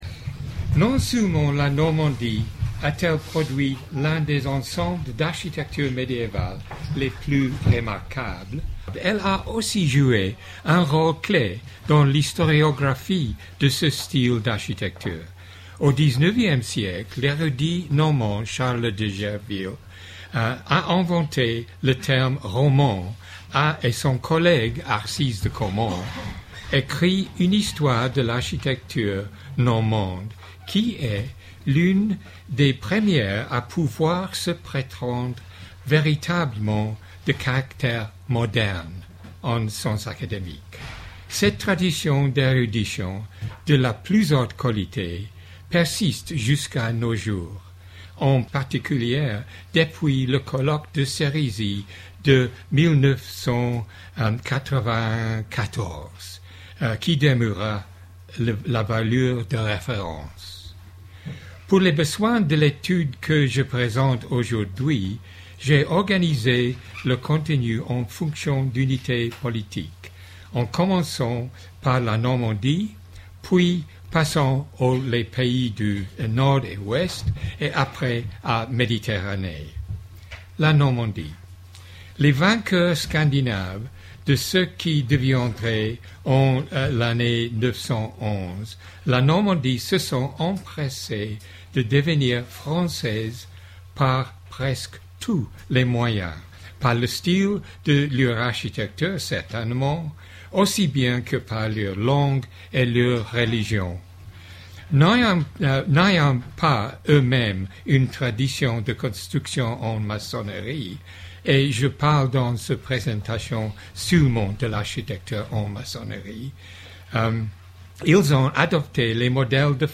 Cette allocution étudie comment l'architecture de la maçonnerie en Normandie découle de celle du Nord de la France, comment les Normands ont contribué ensuite à ces dessins, et dans quelle mesure ces formules ont été modifiées par les pratiques de construction des pays conquis par les Normands, de l'Angleterre et du Pays de Galles jusqu'à la Sicile et à Jérusalem, et par les pays qui ont adopté leurs façons de faire, tels que l'Ecosse, l'Irelande et la Bretagne.